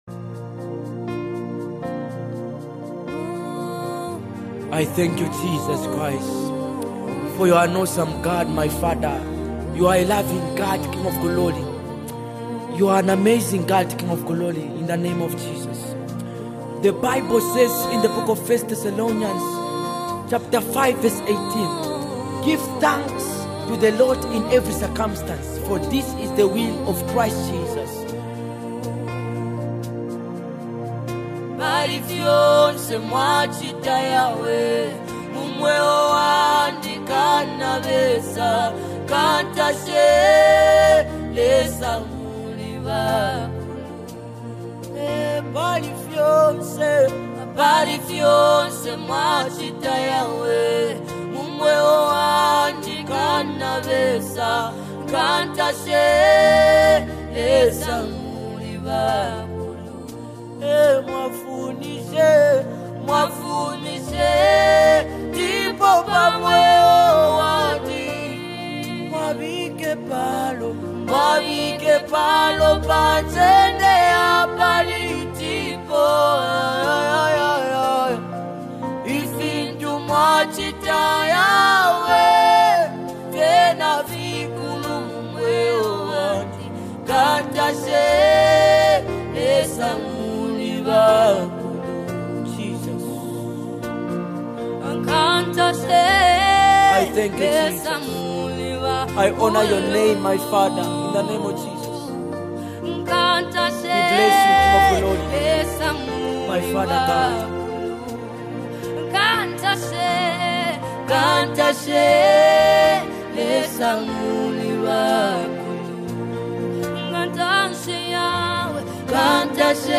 A deeply anointed worship song that speaks to the soul
📅 Category: Zambian Deep Worship Song